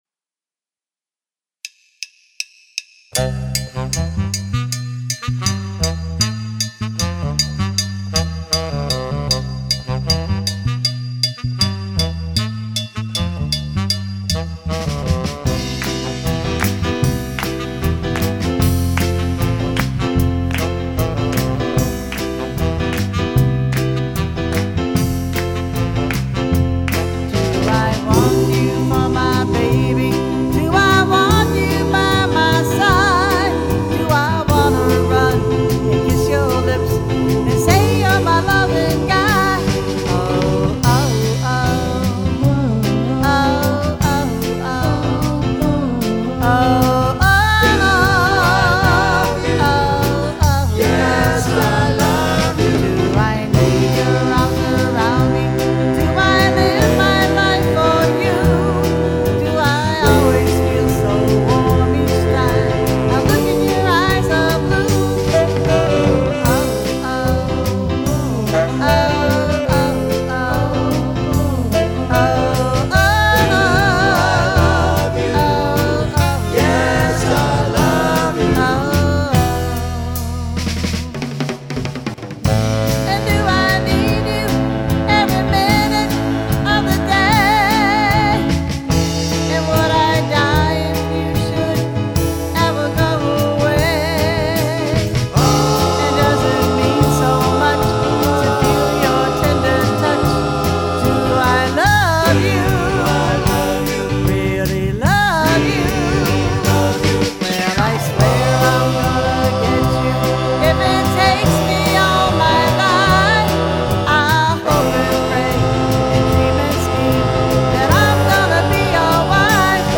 This is my first try at digital recording and mixing.
It was recorded and mixed using a KORG d1600.